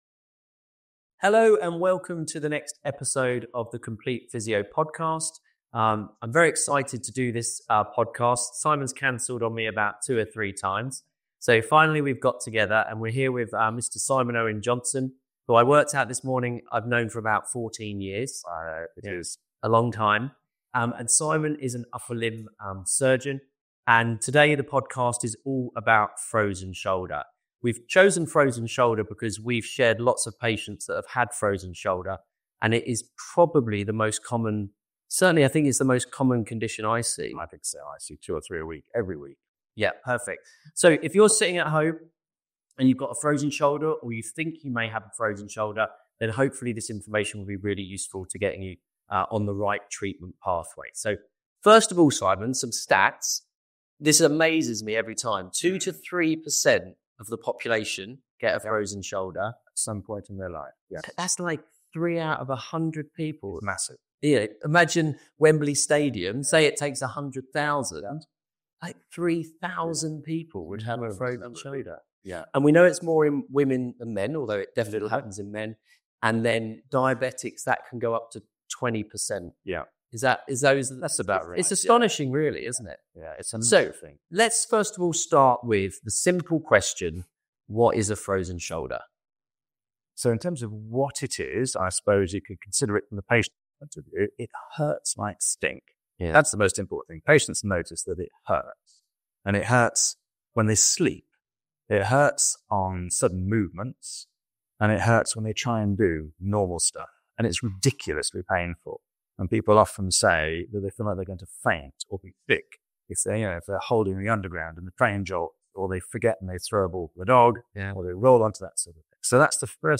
They explore what a frozen shoulder feels like, why it’s so painful, and how to recognise it, even when imaging often shows little to no signs. The conversation covers practical advice on treatments, including steroid injections, physiotherapy, hydrodistension, and surgical options for severe cases.